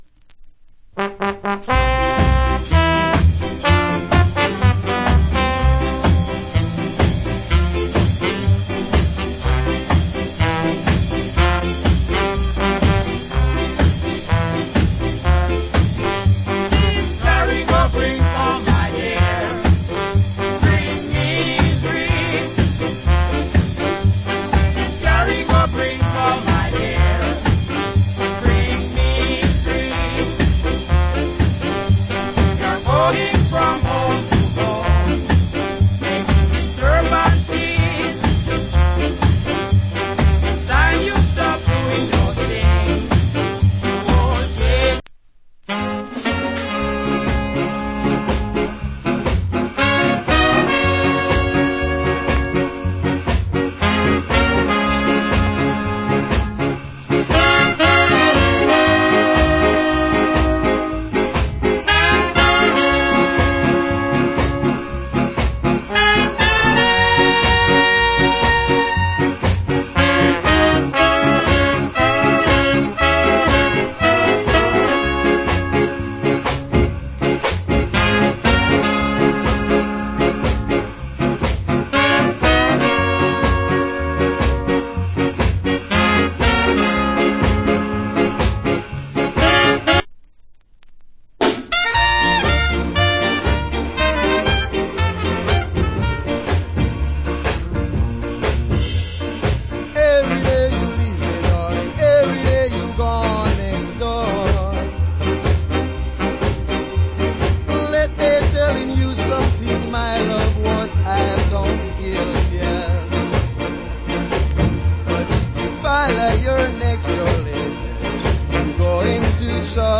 Nice Ska